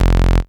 snr_21.wav